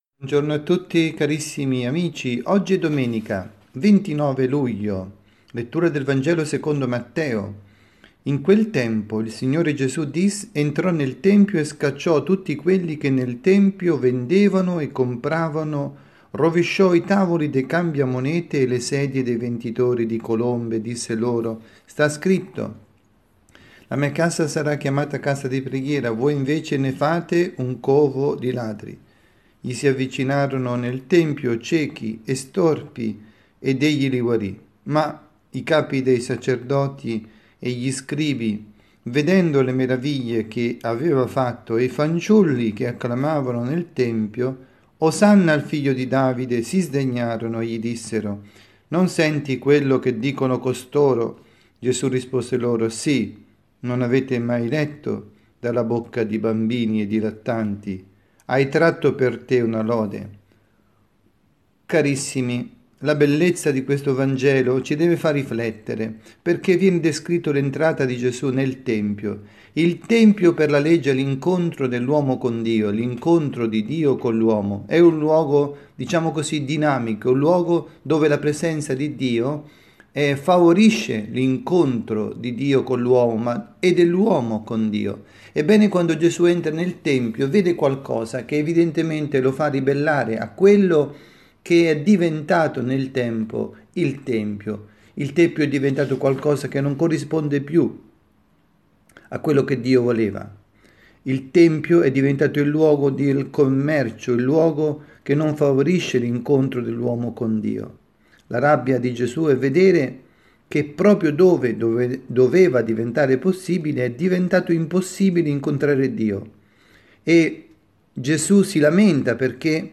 Omelia
dalla Parrocchia S. Rita